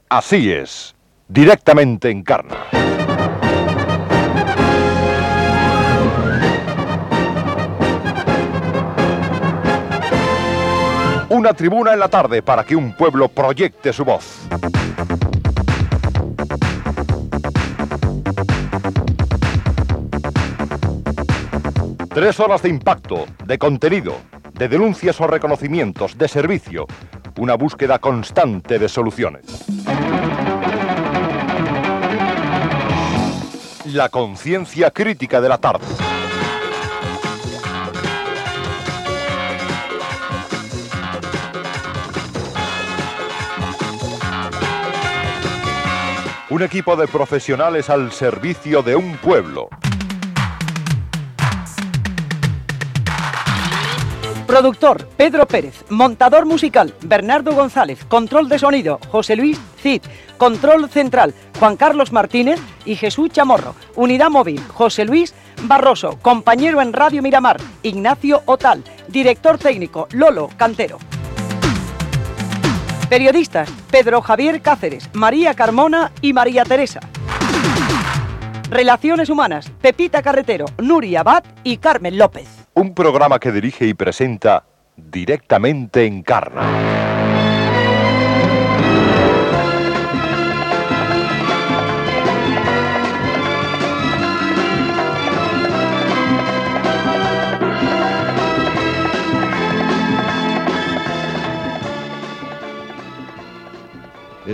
Careta del programa, equip
Info-entreteniment
Programa presentat per Encarna Sánchez.